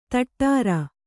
♪ taṭṭāra